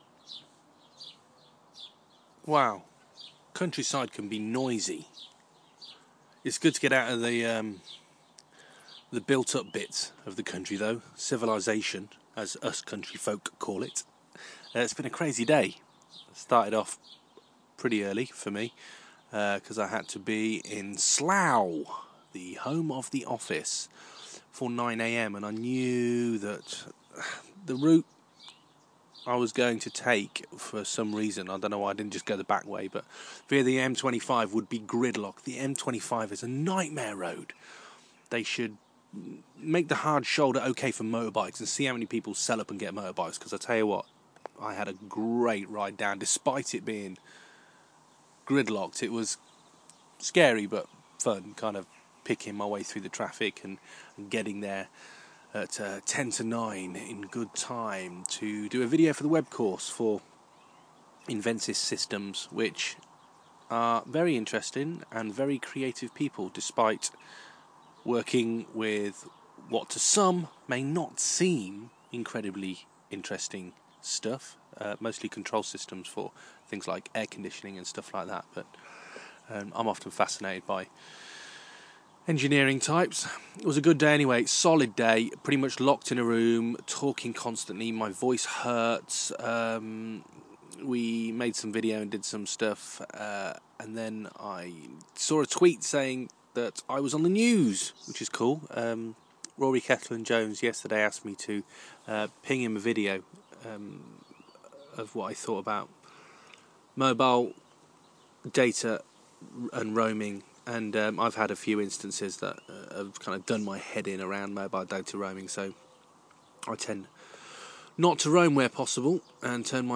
Countryside can be noisy.